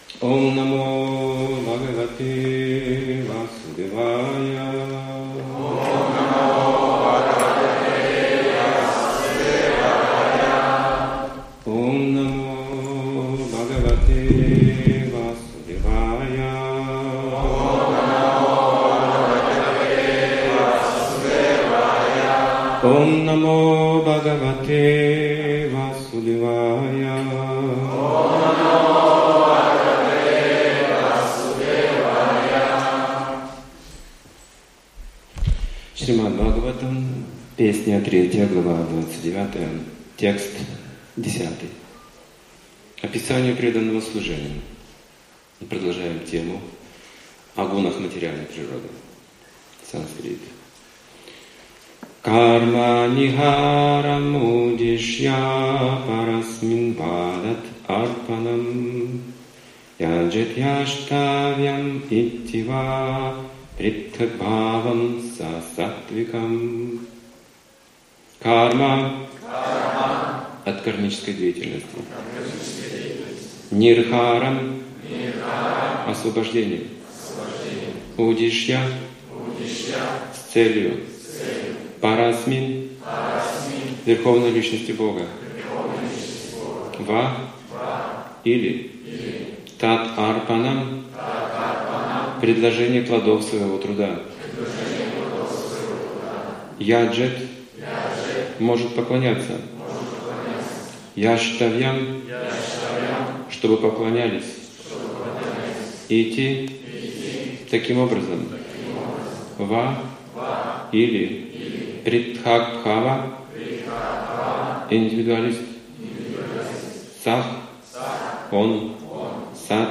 Темы, затронутые в лекции
Екатеринбург